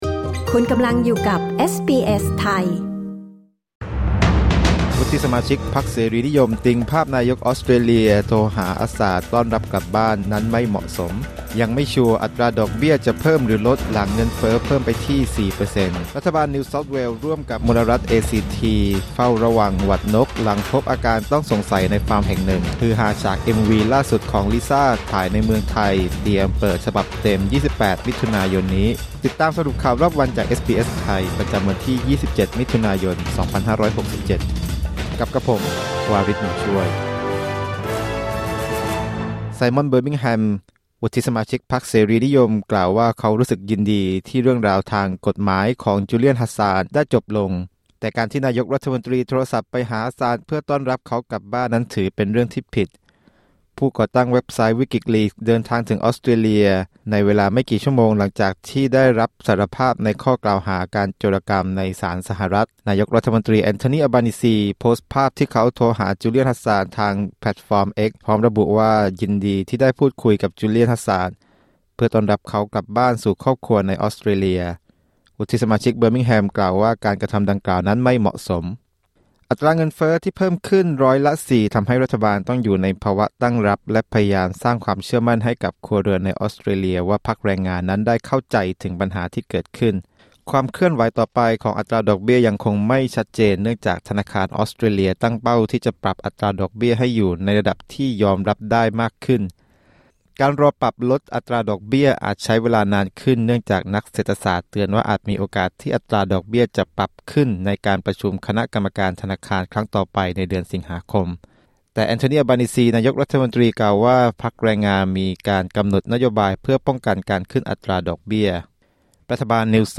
สรุปข่าวรอบวัน 27 มิถุนายน 2567